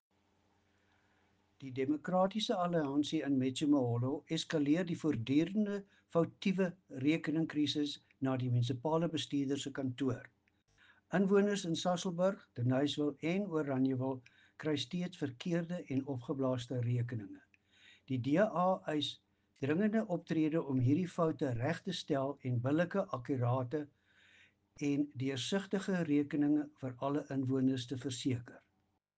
Afrikaans soundbites by Cllr Louis van Heerden and